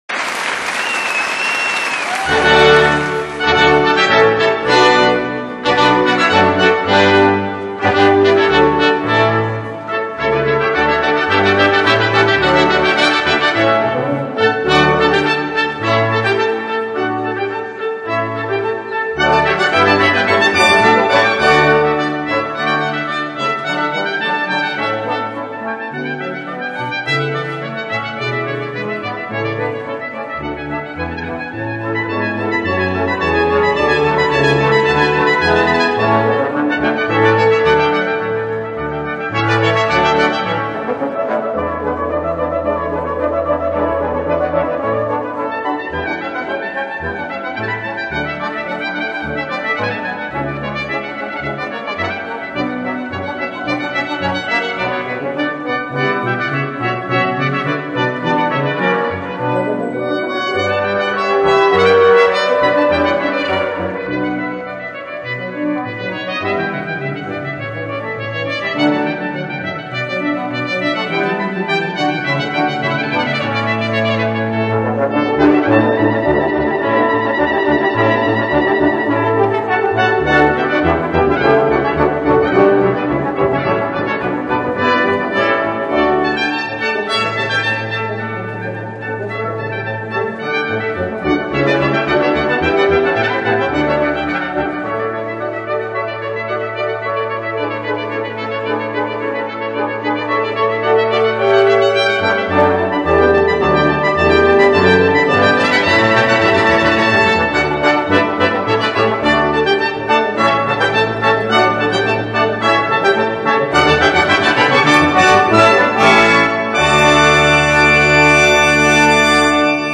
以融古典乐、爵士乐和摇摆乐为一炉的全新方式